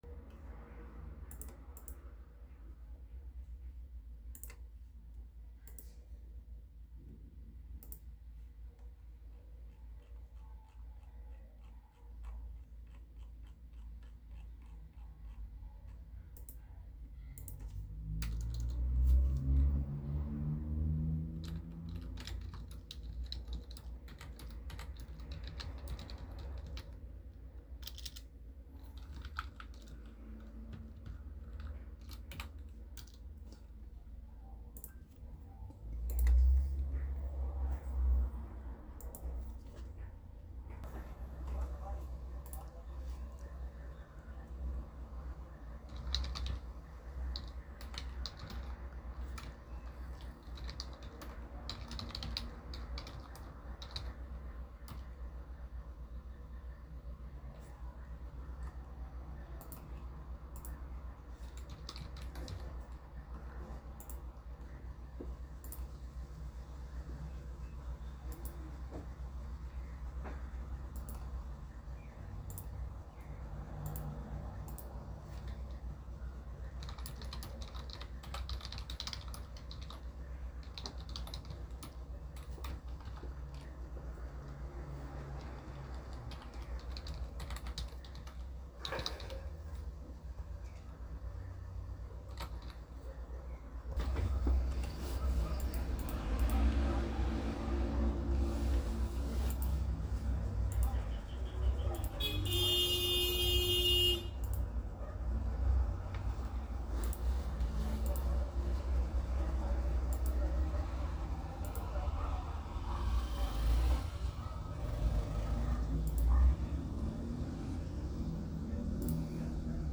Trabajando desde casa ALAJUELA